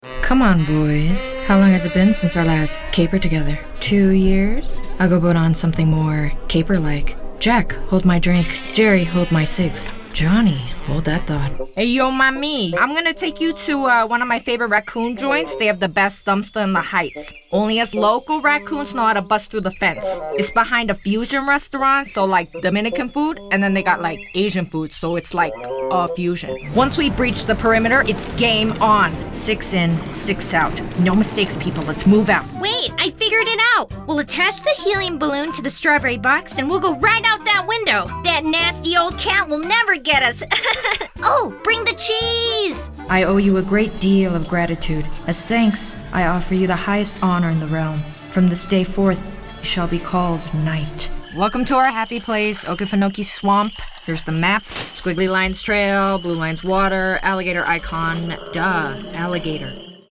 American Voice Over Talent
Teenager (13-17) | Yng Adult (18-29)